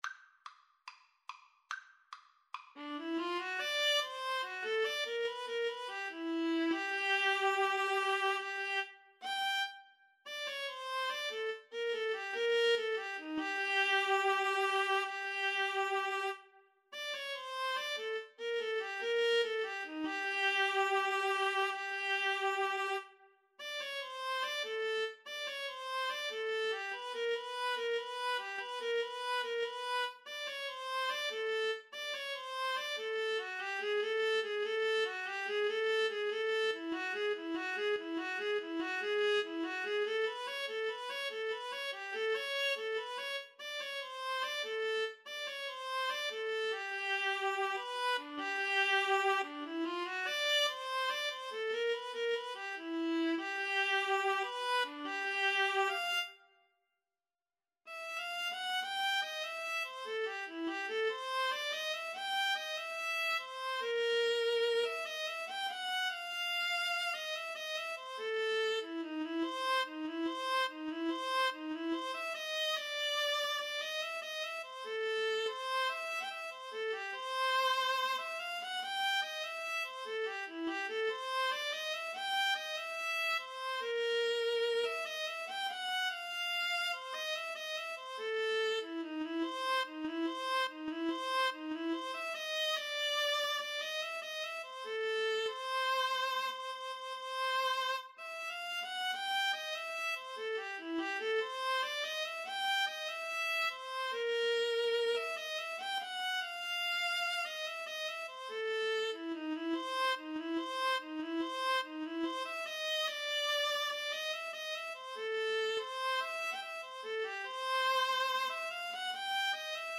Free Sheet music for Viola Duet
Viola 1Viola 2
2/4 (View more 2/4 Music)
G major (Sounding Pitch) (View more G major Music for Viola Duet )
= 72 Moderato
Jazz (View more Jazz Viola Duet Music)